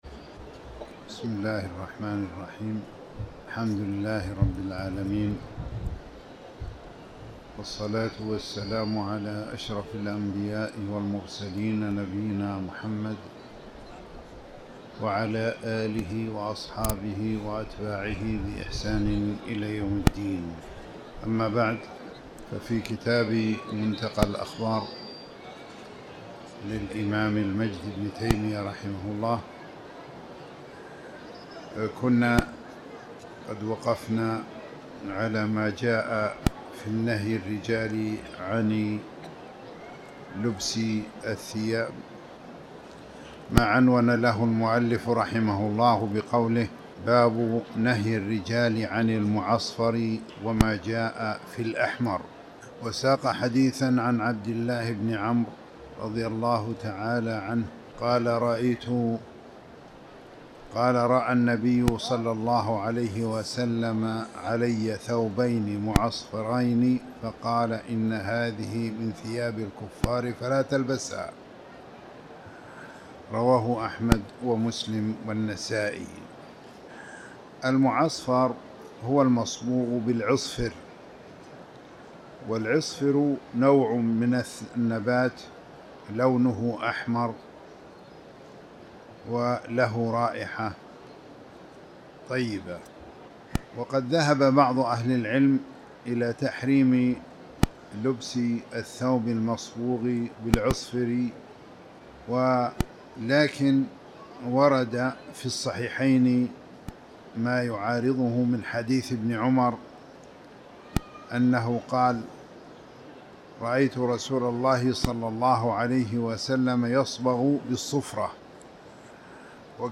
تاريخ النشر ٢ صفر ١٤٤٠ هـ المكان: المسجد الحرام الشيخ